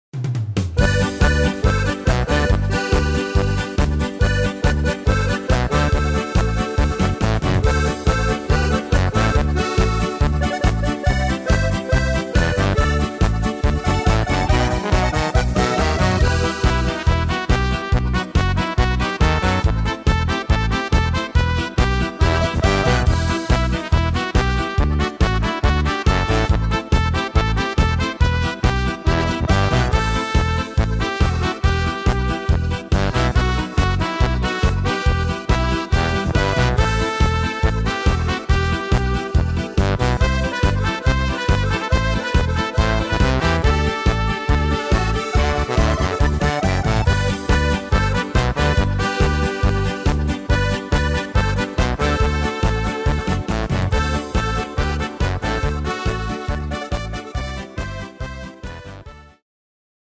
Tempo: 140 / Tonart: G-Dur